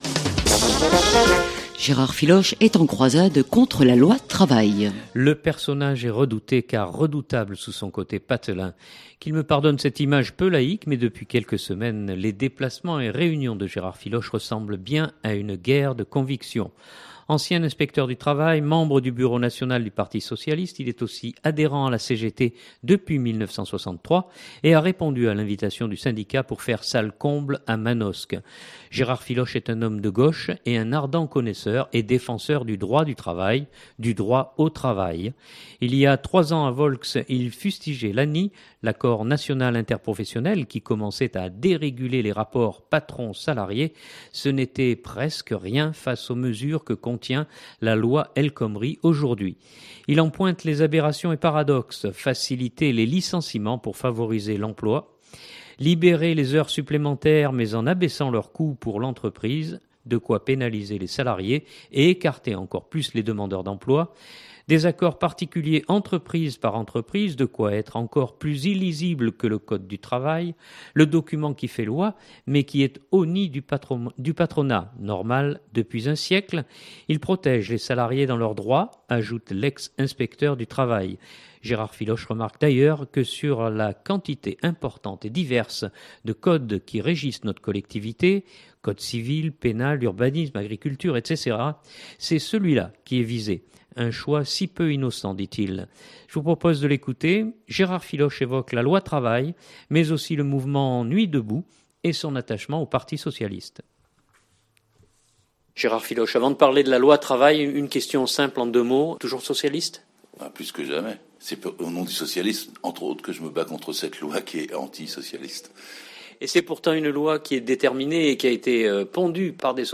Ancien inspecteur du travail, membre du bureau national du parti socialiste, il est aussi adhérent à la CGT depuis 1963 et a répondu à l’invitation du syndicat pour faire salle comble à Manosque.